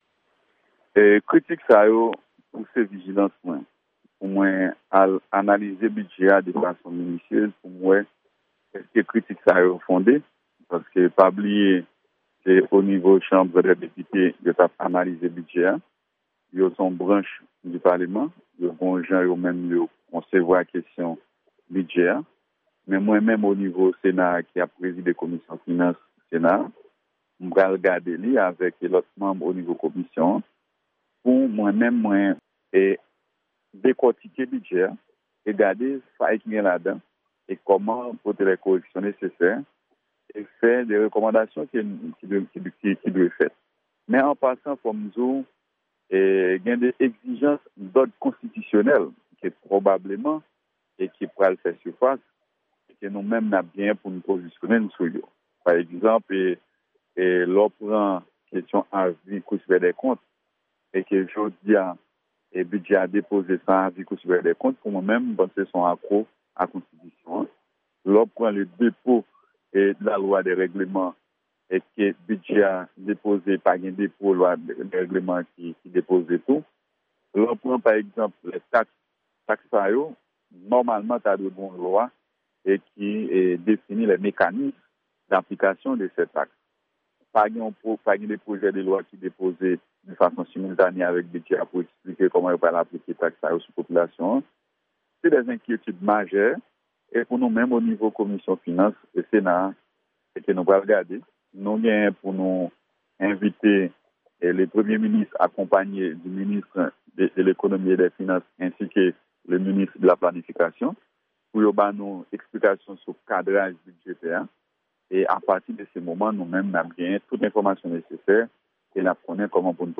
Deklarasyon Senatè Nenel Cassey sou Bidjè Rektifikatif 2017-2018 la